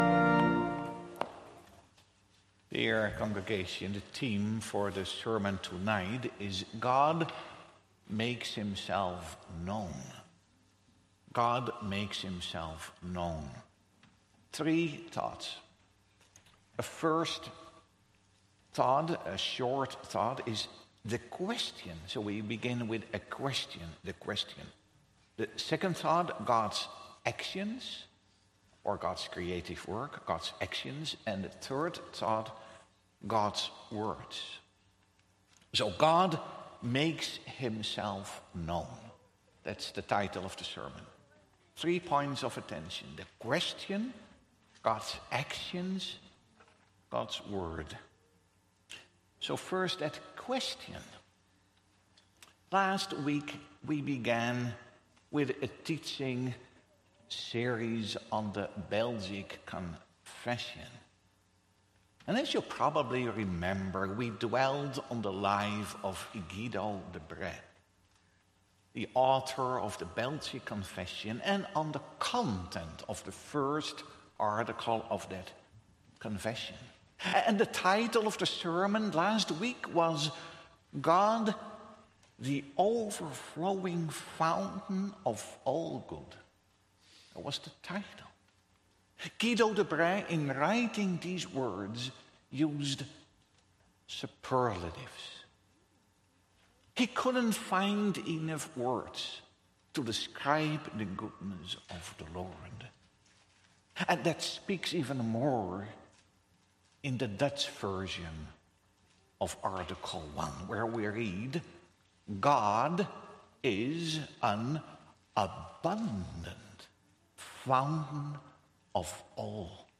Sermon Audio | Providence Reformed Church